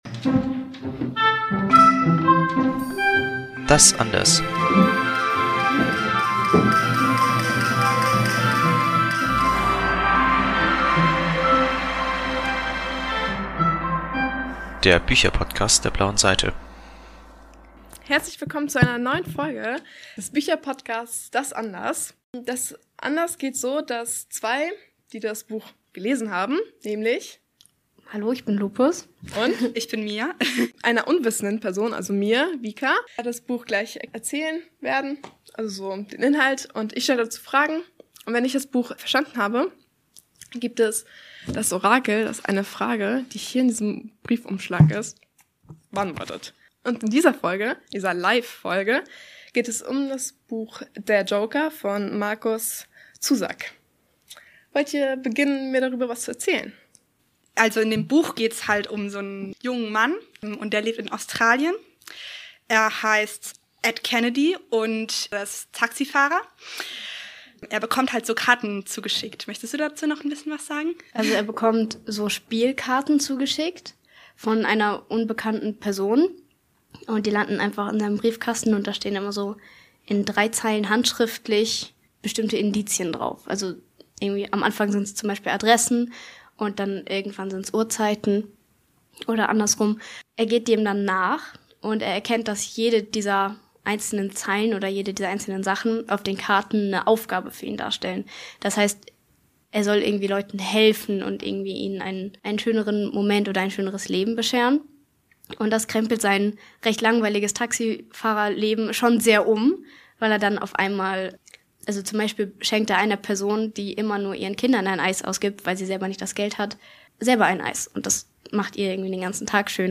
Live-Aufnahme vom Podcast-Marathon "Podcast und Plätzchen" im Dezember 2025 Mehr